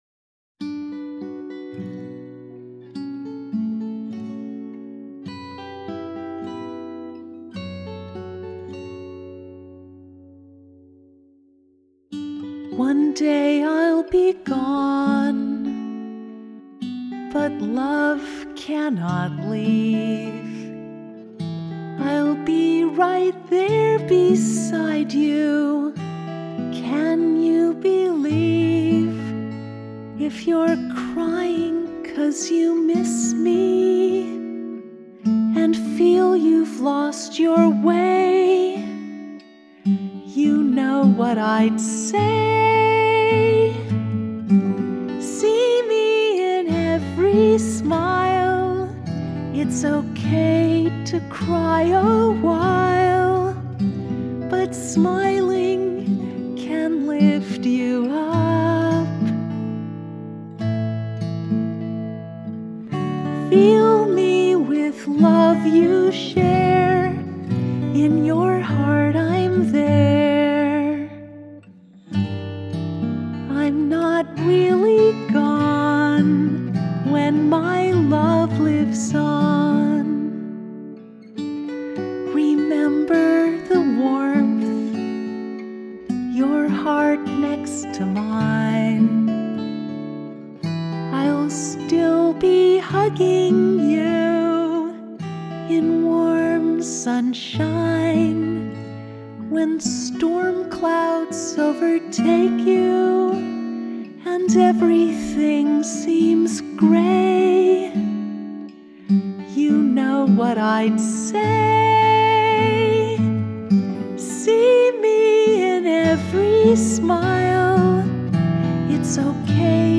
in-every-smile-acoustic-6-4-18.mp3